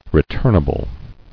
[re·turn·a·ble]